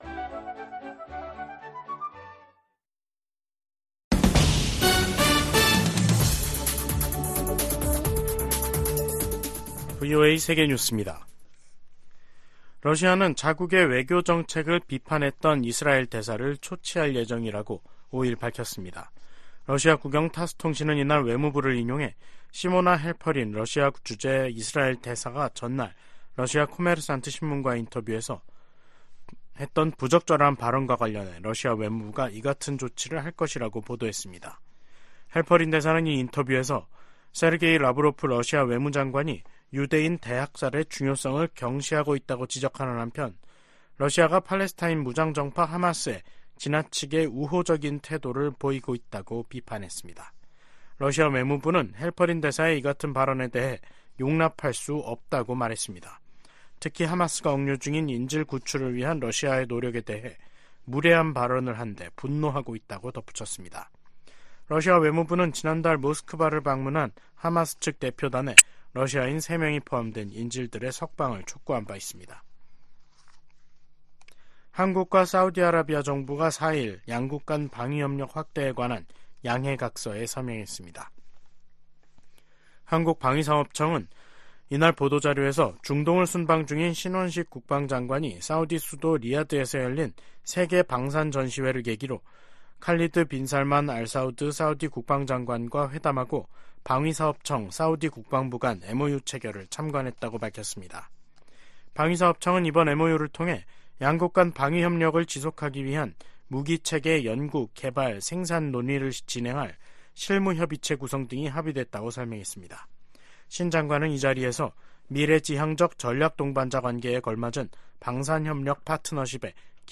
VOA 한국어 간판 뉴스 프로그램 '뉴스 투데이', 2024년 2월 5일 3부 방송입니다. 북한은 순항미사일 초대형 전투부 위력 시험과 신형 지대공 미사일 시험발사를 지난 2일 진행했다고 대외 관영 ‘조선중앙통신’이 다음날 보도했습니다. 미국 정부는 잇따라 순항미사일을 발사하고 있는 북한에 도발을 자제하고 외교로 복귀하라고 촉구했습니다. 미국 정부가 미국내 한인 이산가족과 북한 가족들의 정보를 담은 기록부를 구축하도록 하는 법안이 하원에서 발의됐습니다.